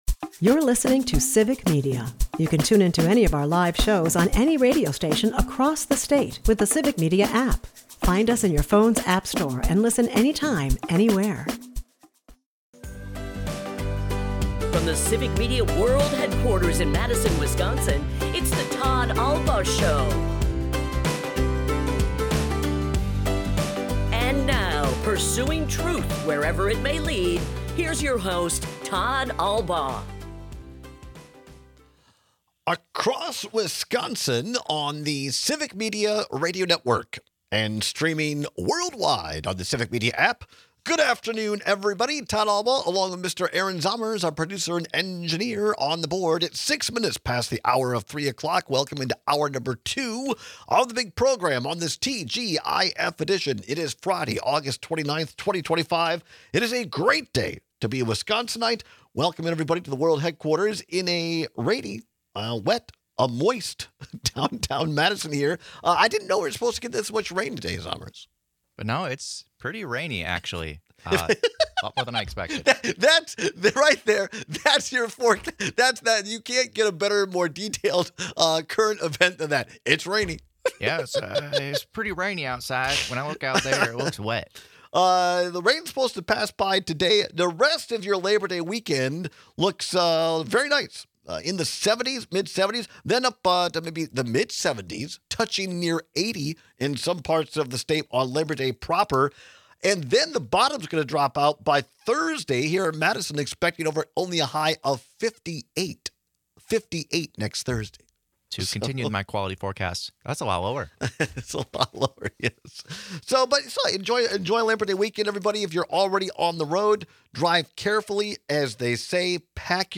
We take calls and texts on whether it hurts more to be arrogant or dejected.